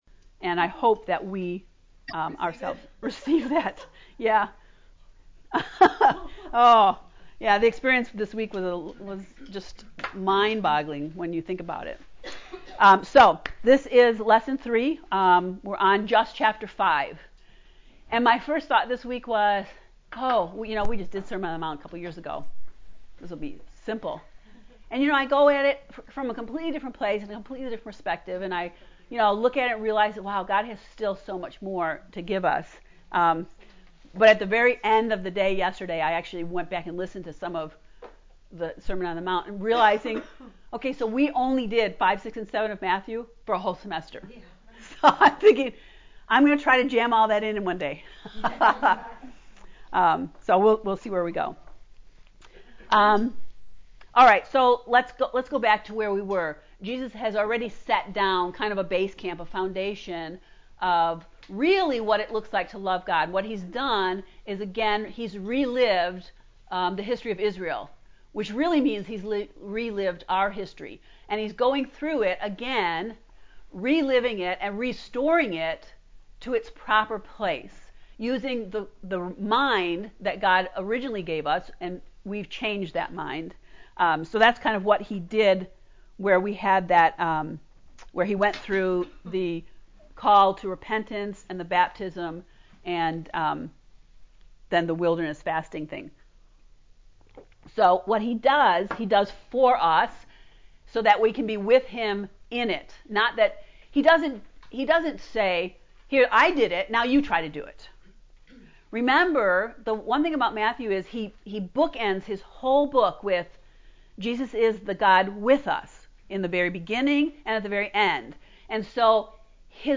To listen to the lecture on lesson 3 “Breathing Kingdom Air” click below: